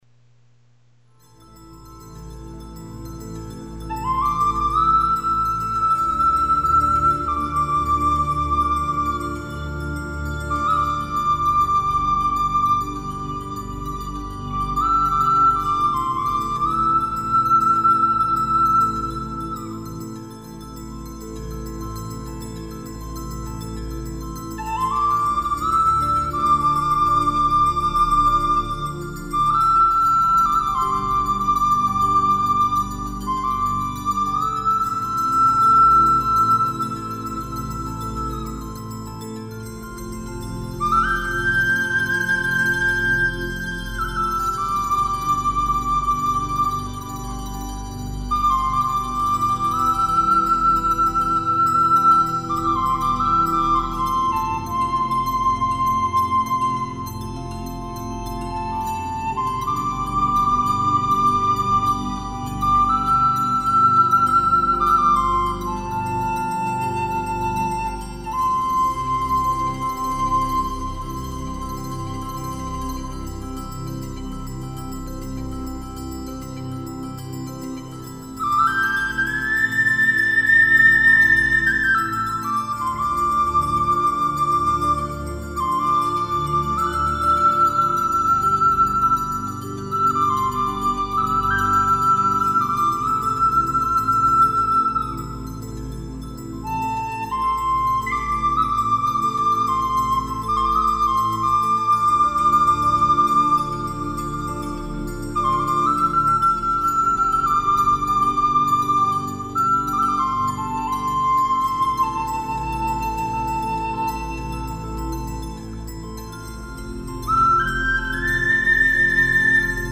An ambient soundscape with heavy use of asian bells.
Here is an MP3 file of the same overdub.